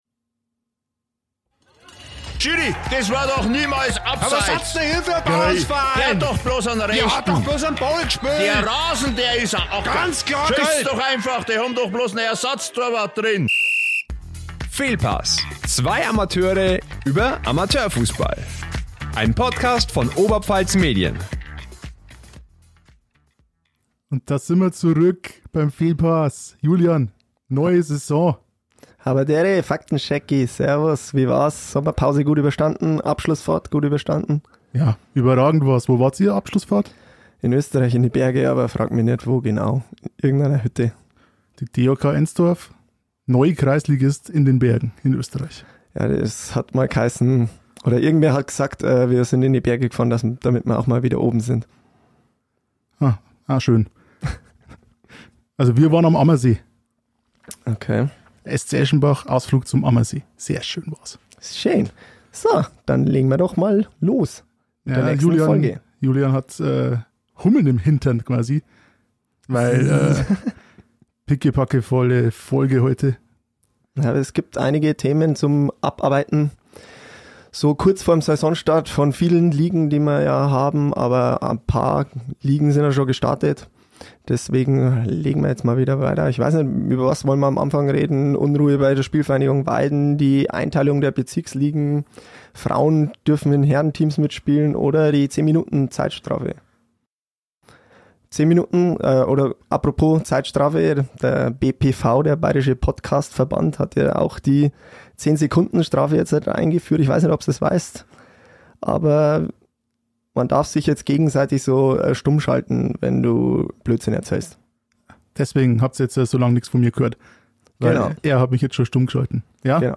Wer wird Meister in Bezirksliga bis Kreisklasse? Das "Fehlpass"-Duo tippt gegen einen Experten und spricht über die Aufreger der Vorbereitung.